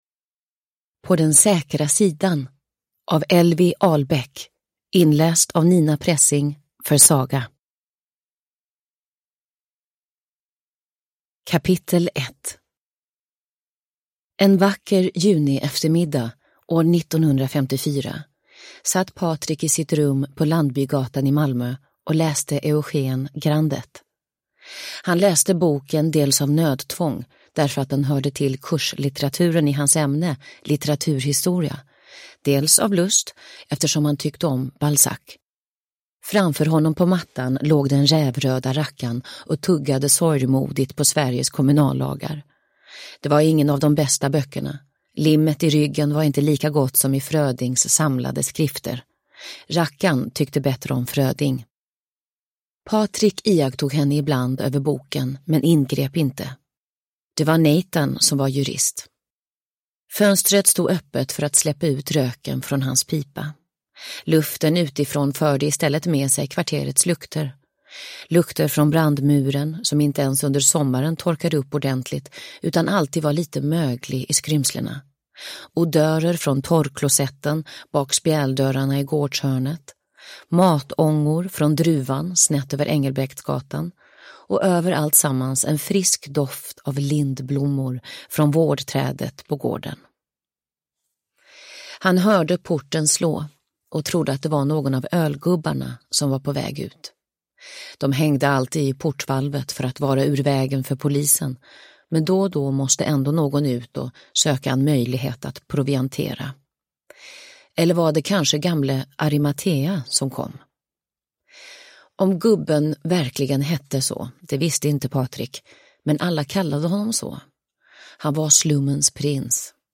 På den säkra sidan – Ljudbok – Laddas ner